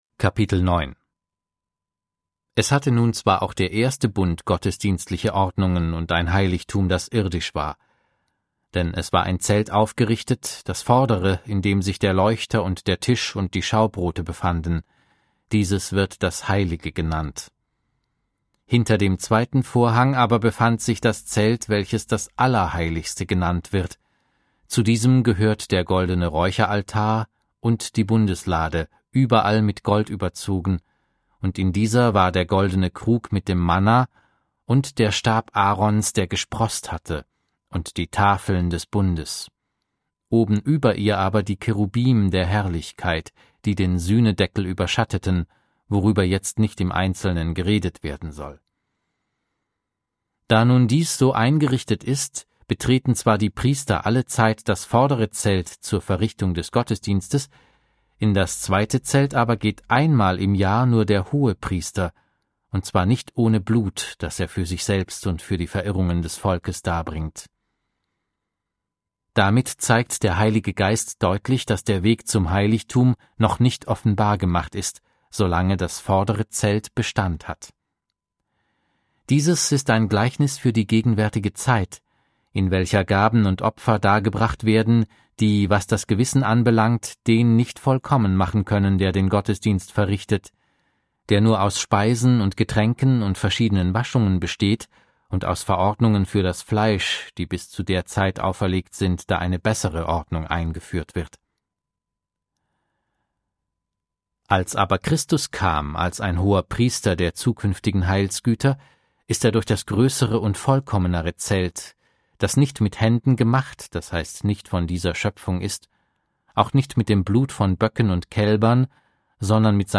Audio Bibel - Schlachter 2000